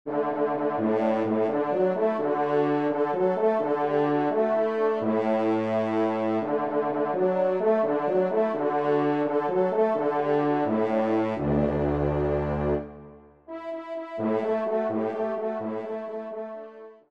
Trompe 4